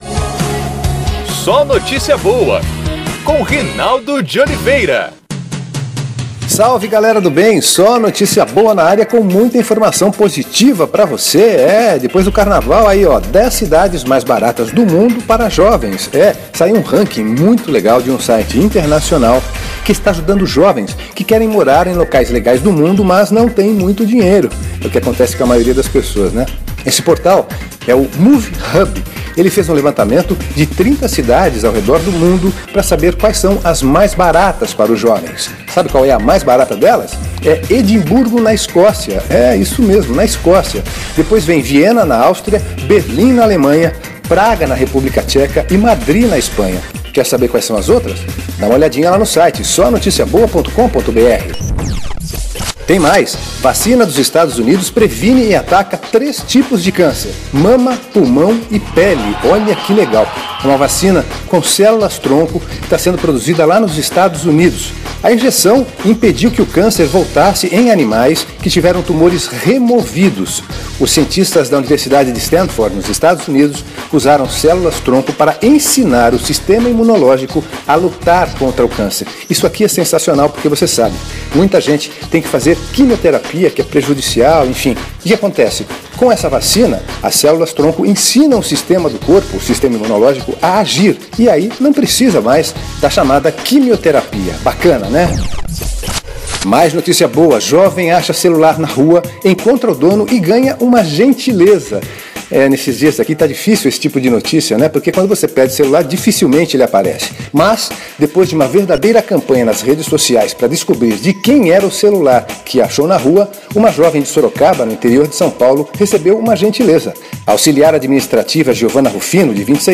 As boas mais legais você ouve aqui e também em pílulas na programação da Rádio Federal.